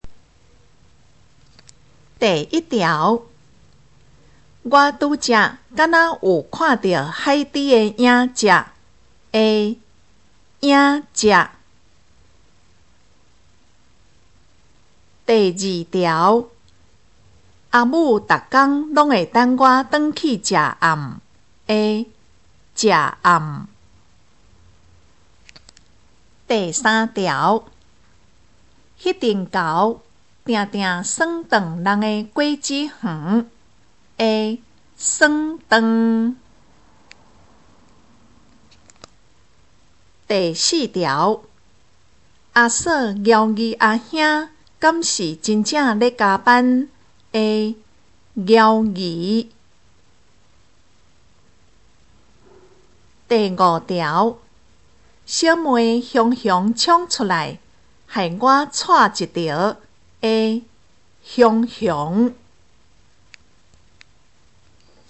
【國中閩南語4】每課評量(3)聽力測驗mp3